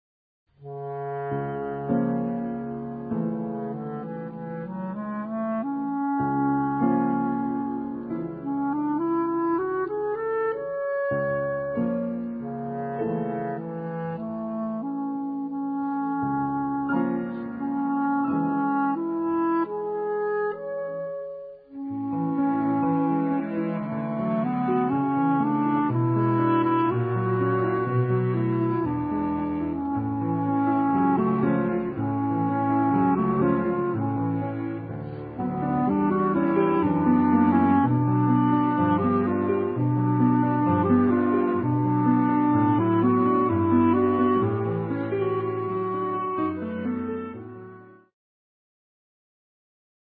Cello
Grand Piano